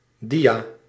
Ääntäminen
IPA : /slaɪd/ US : IPA : [slaɪd]